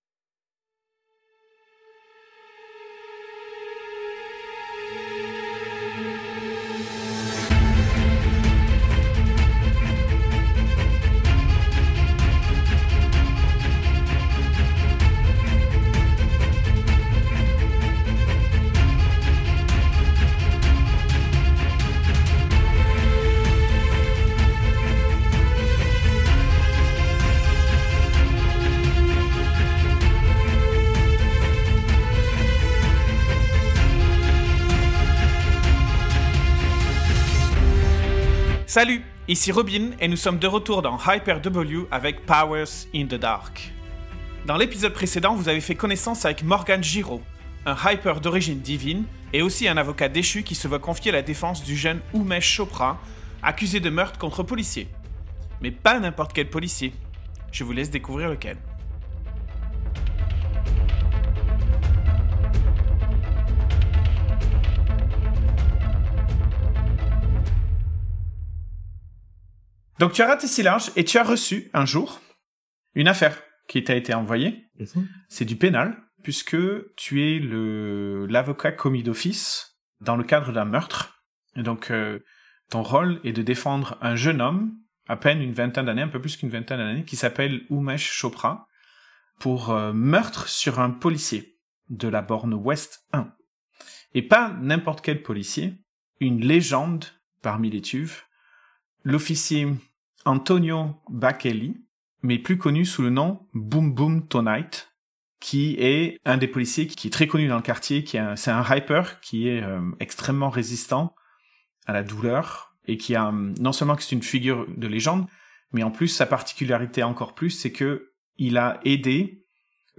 Taser/High Voltage discharge